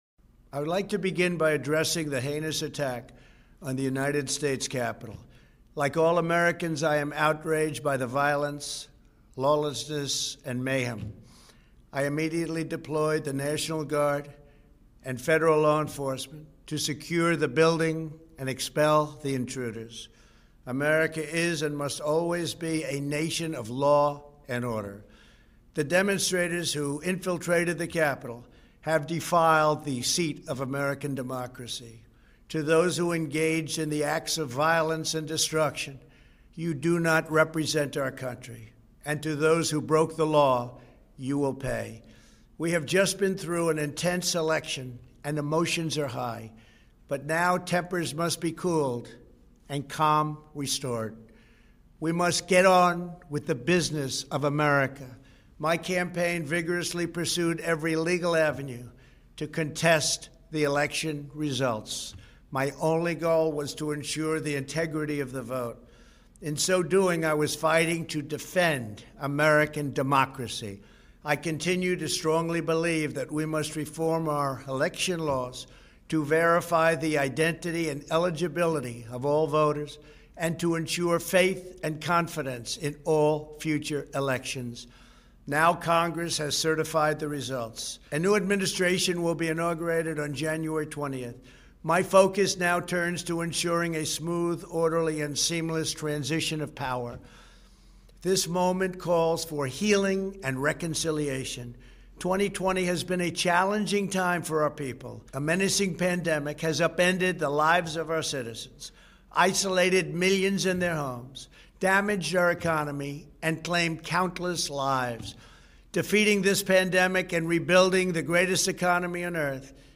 Message from President Trump